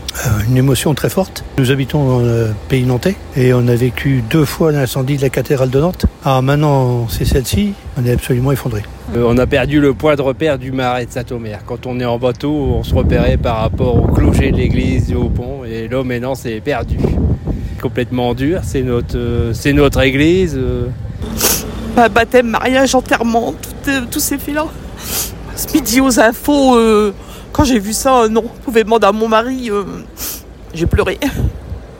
Nous sommes allés à la rencontre des habitants du haut pont et des personnes qui s’étaient réunies hier devant l’édifice ,LOGO HAUT PARLEUR Écoutez
TEMOIGNAGE.mp3